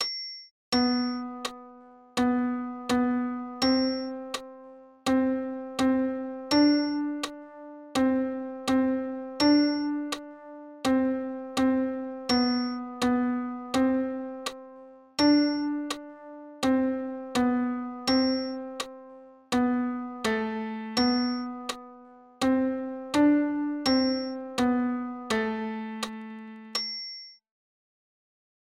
Accordeon diatonique et Musiques Traditionnelles
Gamme de Do tiré croisé
Premier plan : repérage du bloc de touches LA SI DO RE, en croisé et en tirant
En haut de clavier boutons 3, 2', 4, 3 = La Si Do et Ré en utilisant deux doigts 1=index et 2=majeur.